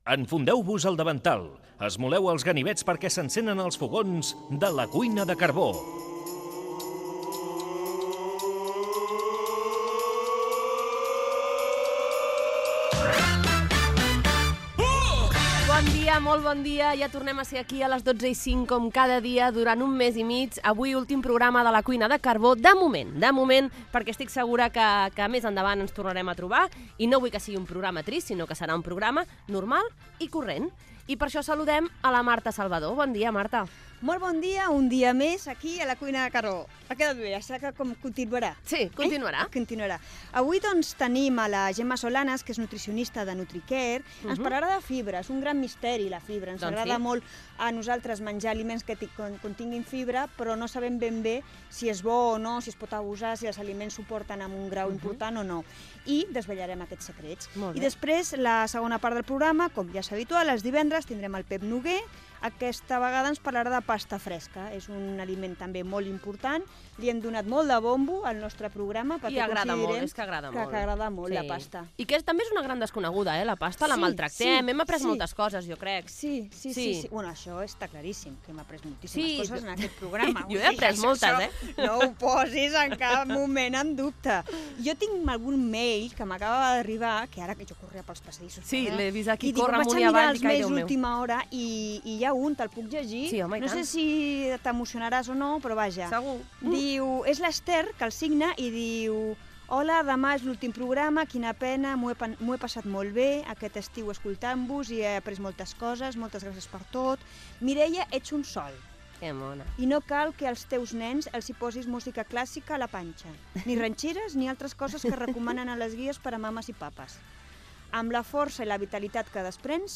Inici de l'últim programa de la temporada d'estiu.
Divulgació